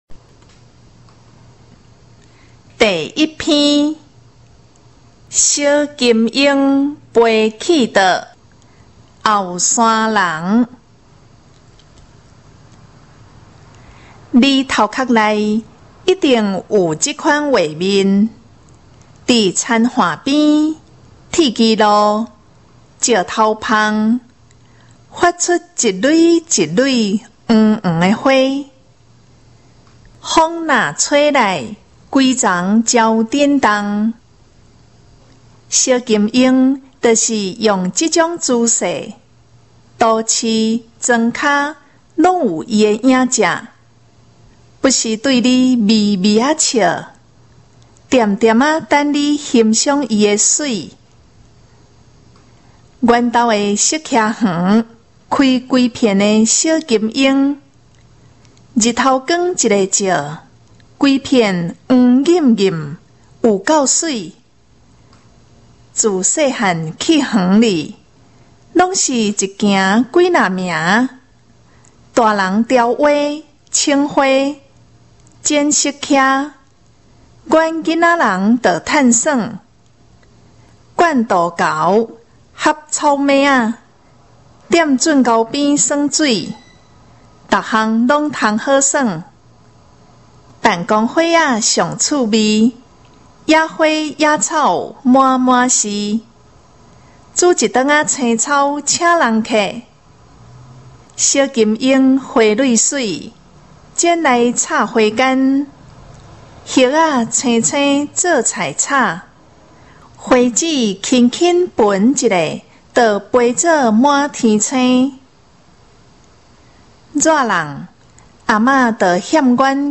105學年度下學期閩南語朗讀文字及語音檔